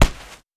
Gloves Hit Normal.wav